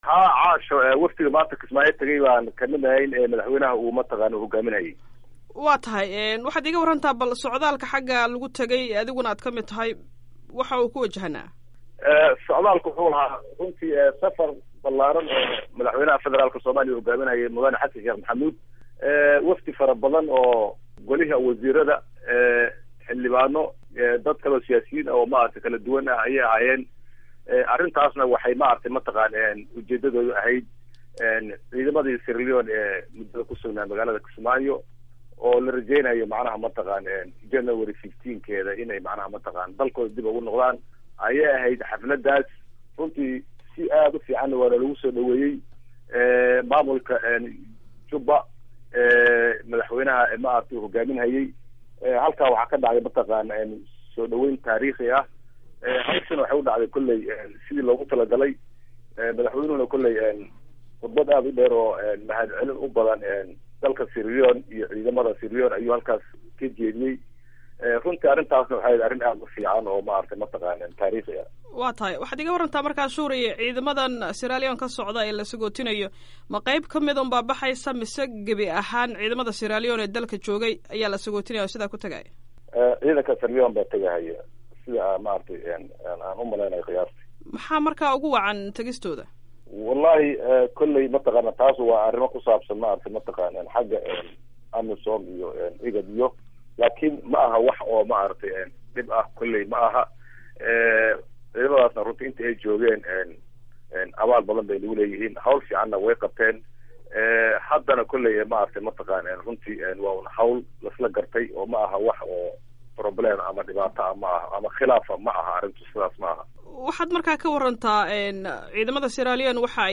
Waraysiga Ciidanka Sierra Leon